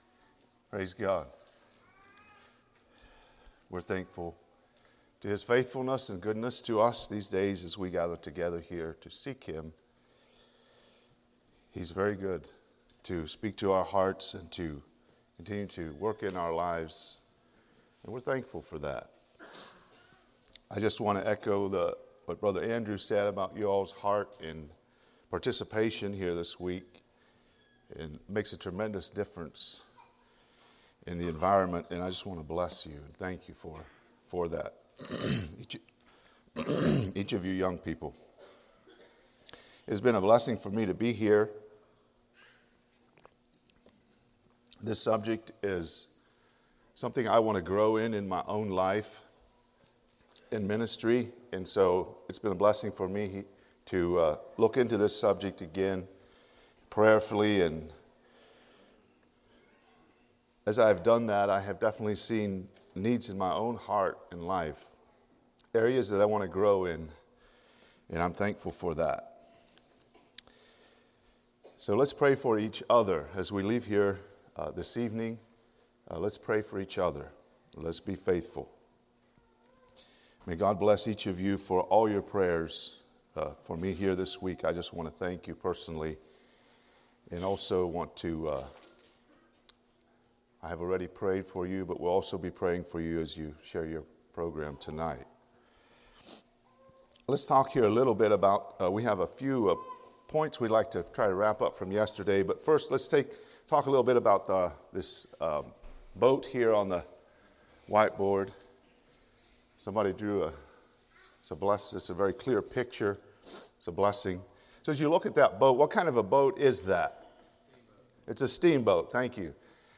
A message from the series "Bible Boot Camp 2024."